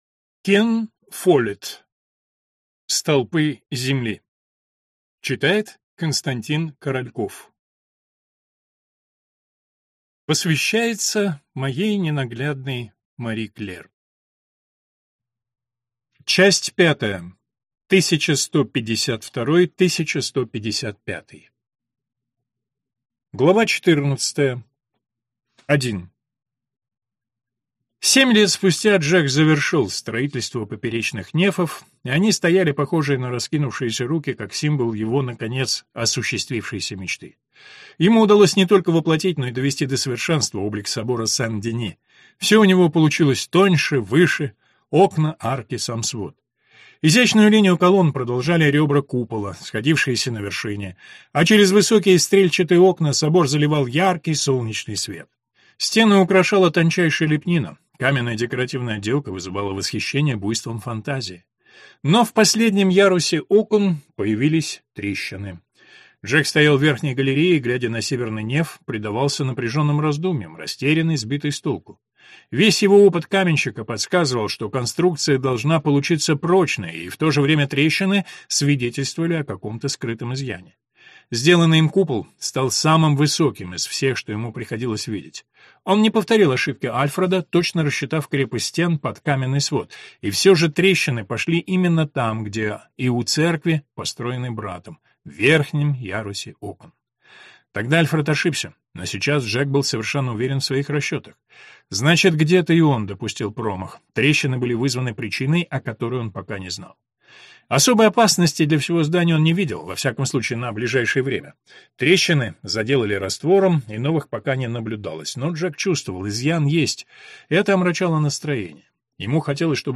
Аудиокнига Столпы земли. Часть 3 | Библиотека аудиокниг